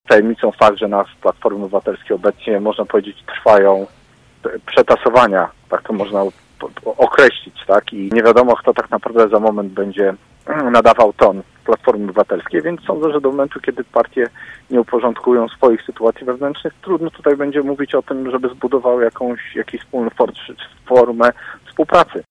Komentarz radnego SLD
Miejski radny Sojuszu Lewicy Demokratycznej był gościem „Rozmowy po 9”.